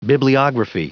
Prononciation du mot bibliography en anglais (fichier audio)
Prononciation du mot : bibliography